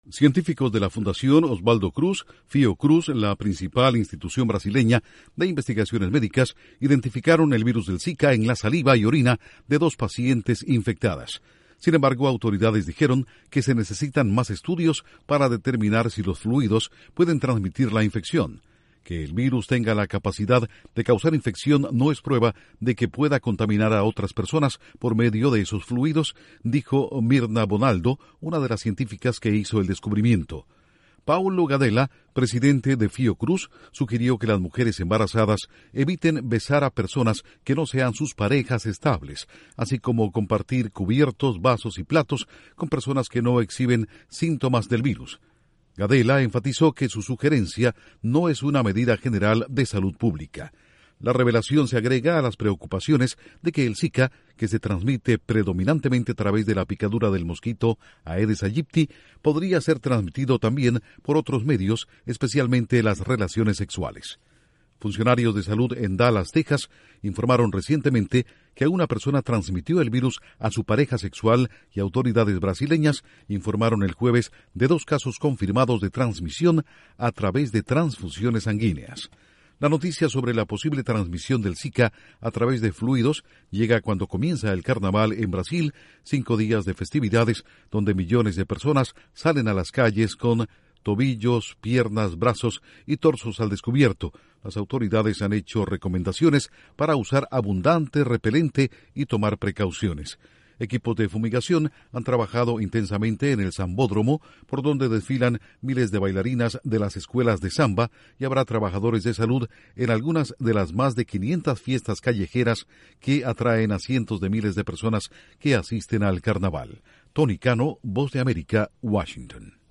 Científicos Identifican el virus del Zika en saliva y orina. Informa desde la Voz de América en Washington